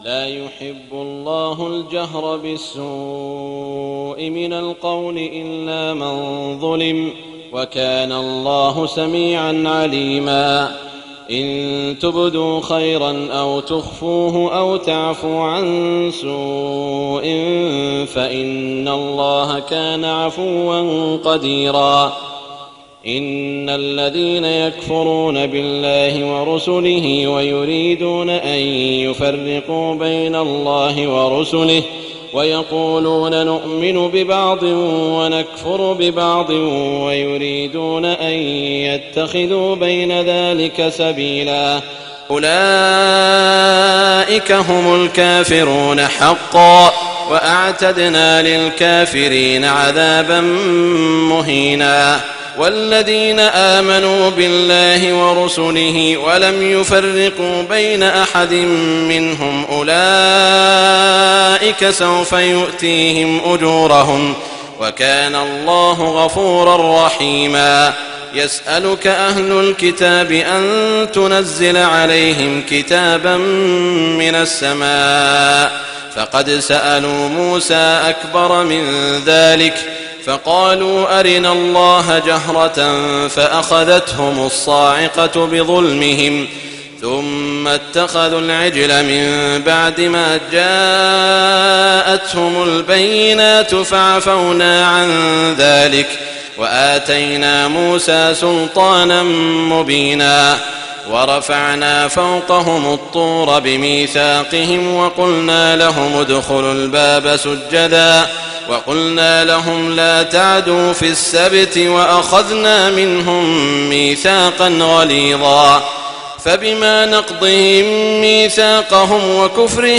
تهجد ليلة 26 رمضان 1419هـ من سورتي النساء (148-176) و المائدة (1-40) Tahajjud 26 st night Ramadan 1419H from Surah An-Nisaa and AlMa'idah > تراويح الحرم المكي عام 1419 🕋 > التراويح - تلاوات الحرمين